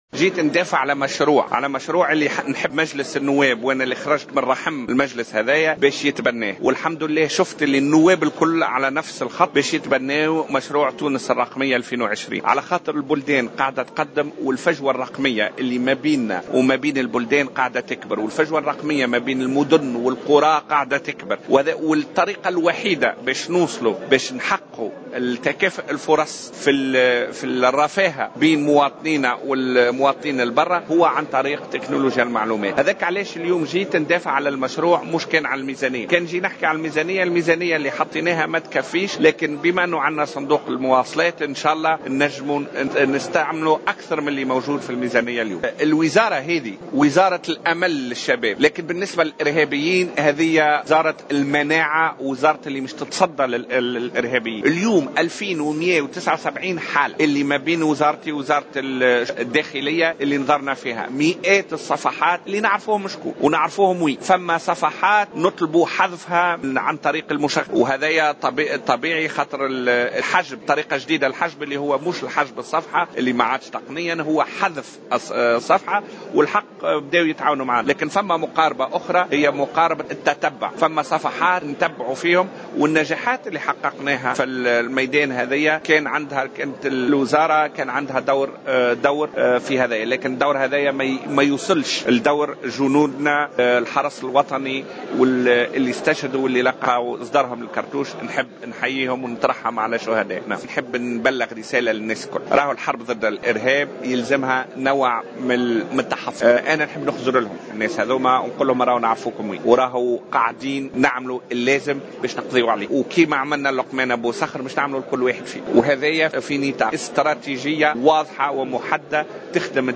قال وزير تكنولوجيّا الاتصال والاقتصاد الرقمي نعمان الفهري ،اليوم في مداخلة له في الجلسة العامة المخصصة لمناقشة ميزانية وزارة تكنولوجيّا الاتصال والاقتصاد الرقمي أن حضوره اليوم في هذه الجلسة يأتي بغاية الدفاع عن مشروع تونس الرقميّة 2020 لتبني هذا المشروع في ظل اتساع الفجوة الرقميّة بين تونس و دول العالم و هذا ما استحسنه نواب المجلس تشجيعا على التطور التكنولوجي .